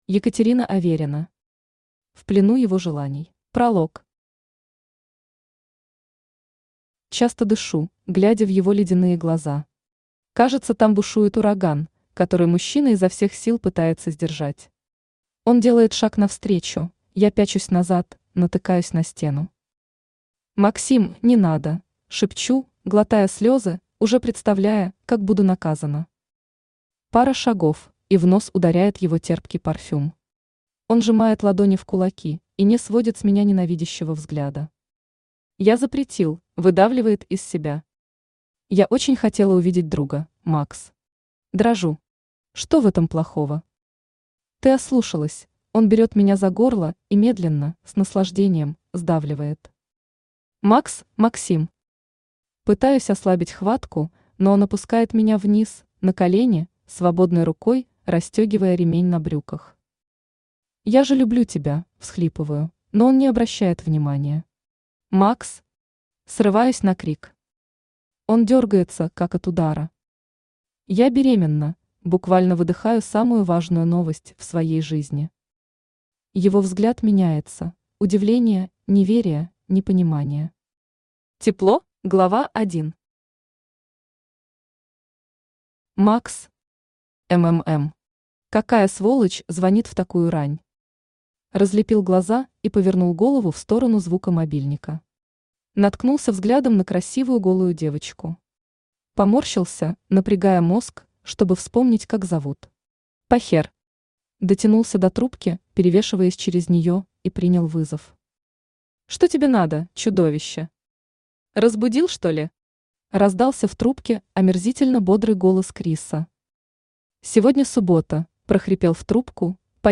Аудиокнига В плену его желаний | Библиотека аудиокниг